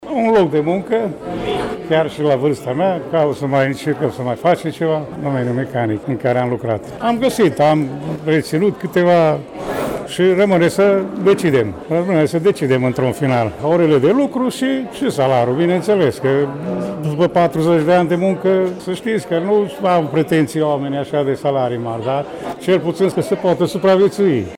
La eveniment s-au prezentat atât muncitori cu experiență cât și persoane care încă nu au pătruns pe piața muncii. Formatului bursei a permis relaționarea directă cu angajatorii și s-au obținut ușor locuri de muncă, susține unnul din participanți: